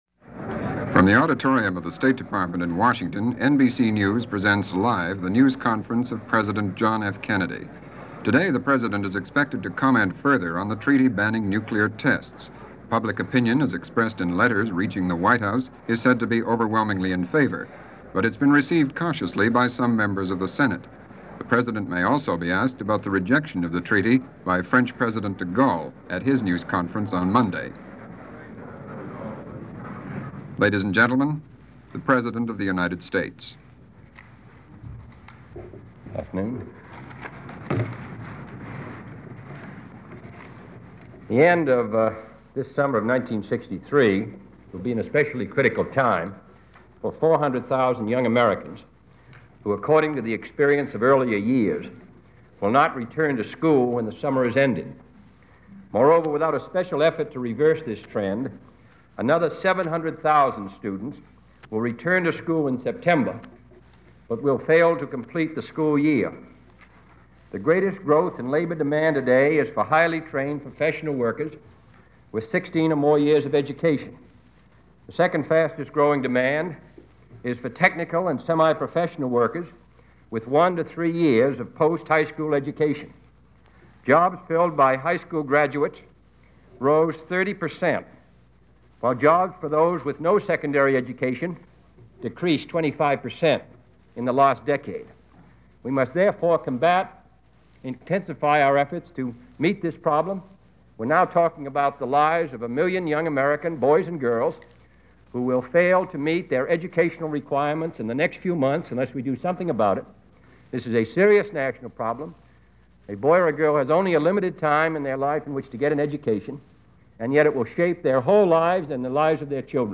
A press conference by President Kennedy – given on August 1, 1963.
JFK-Press-Conference-Aug.-1-1963.mp3